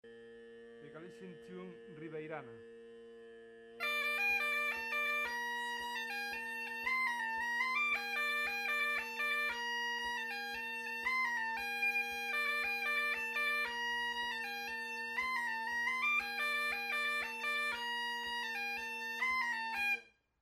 Galician music, bagpipe, gaita, percussion instruments, musical genres, Celticity